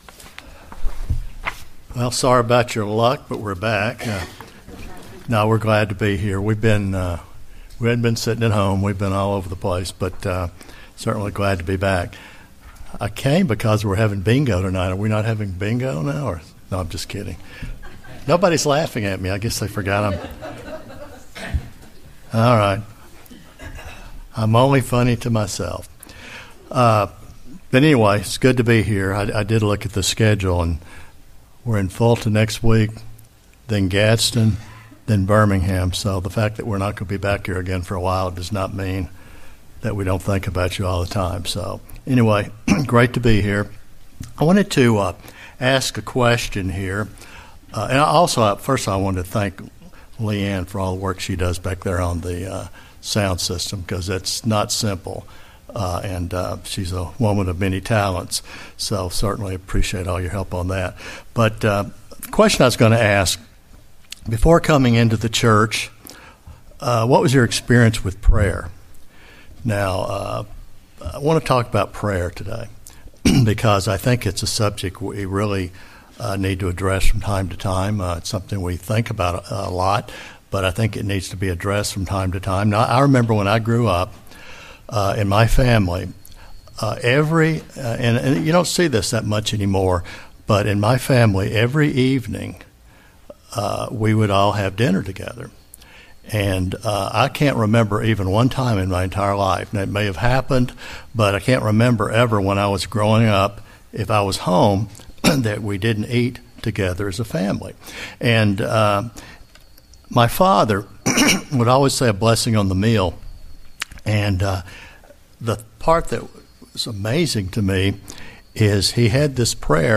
In this sermon we review the topic of prayer.